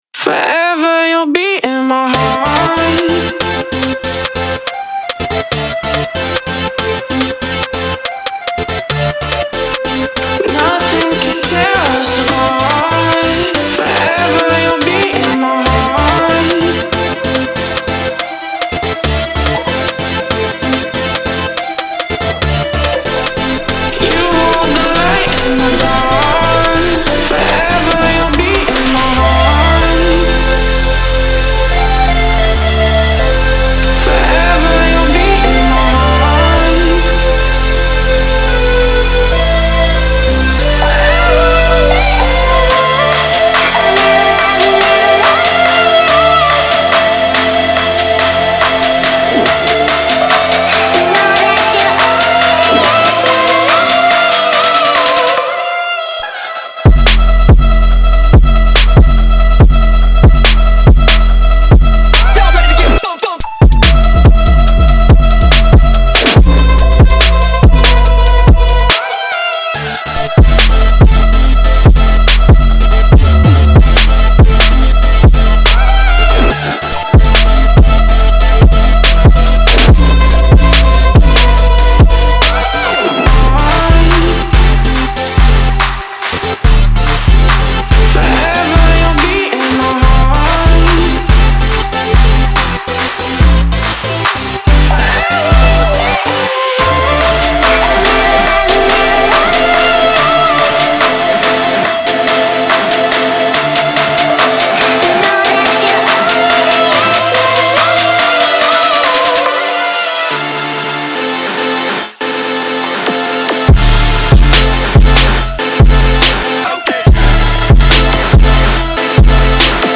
• Жанр: Trap